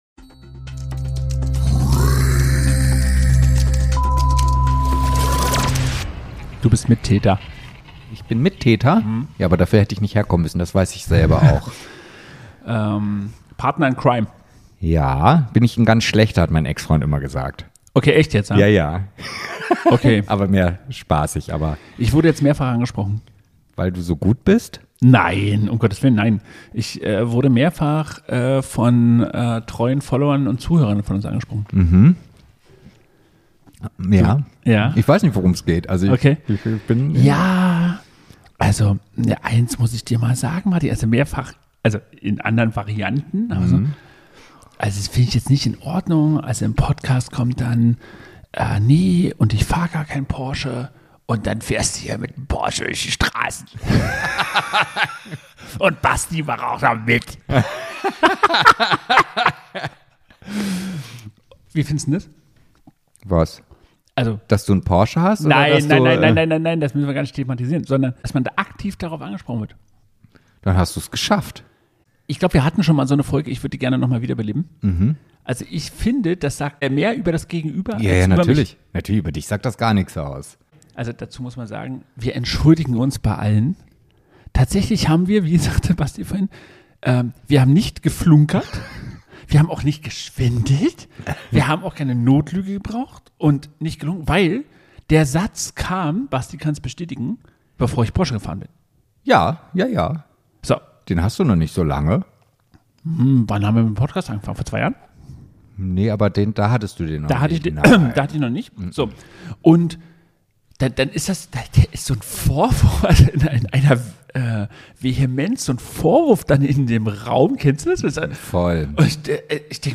Ein Gespräch, das mal lacht, mal denkt – und definitiv alles andere als heilig ist.